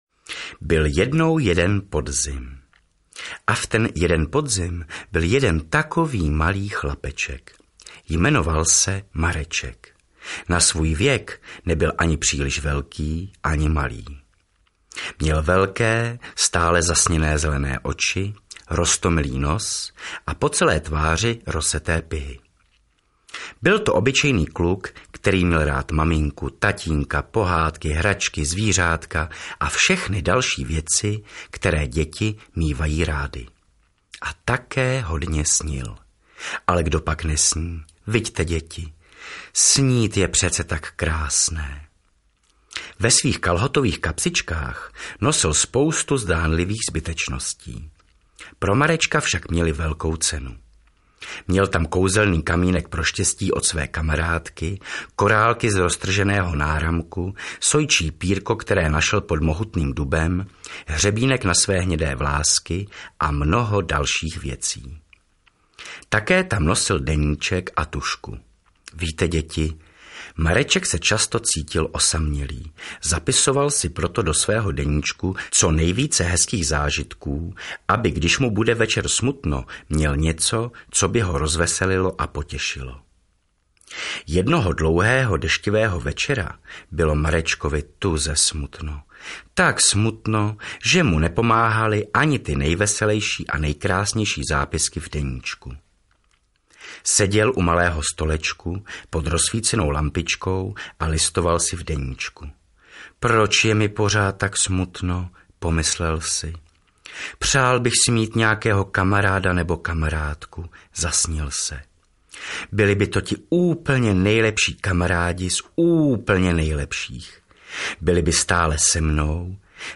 AudioKniha ke stažení, 11 x mp3, délka 40 min., velikost 35,8 MB, česky